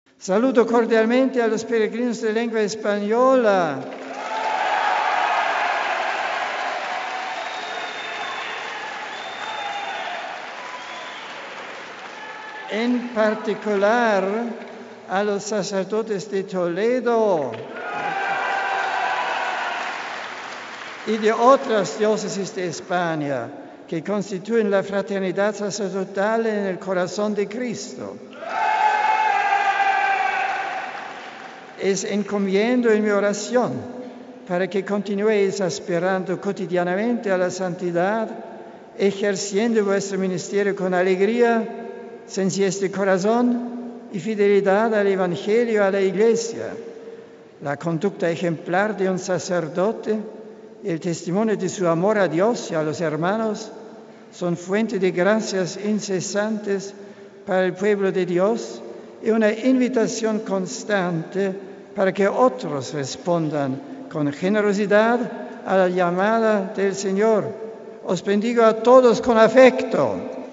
Ante más de 4.000 fieles congregados en el patio del palacio Apostólico de Castelgandolfo, el Santo Padre ha celebrado la tradicional Audiencia General de los miércoles expresando además, solidaridad a la nación polaca, afectada en días pasados por una grave ola de mal tiempo que ha causado numerosas víctimas y graves daños.
“A cuantos han sufrido un daño quiero asegurarles mi cercanía espiritual y el recuerdo en mi oración”, ha finalizado el Papa saludando acto seguido en español: RealAudio